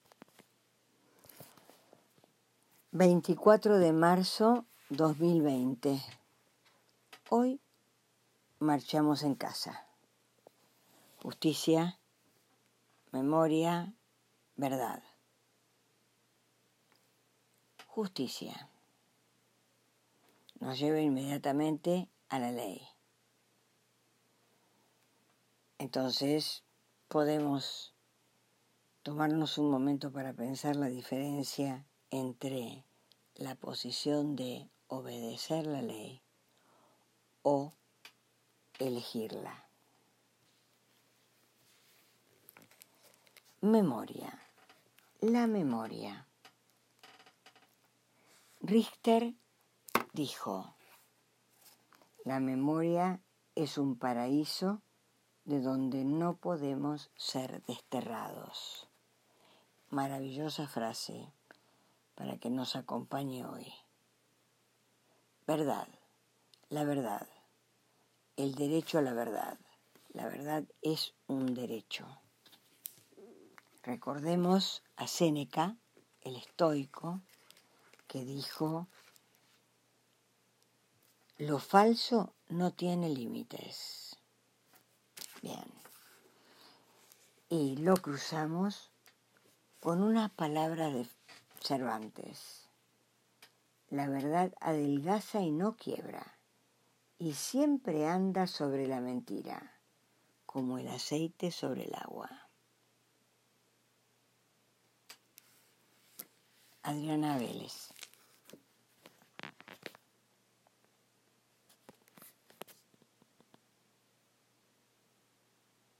también marcha desde su casa soltando la voz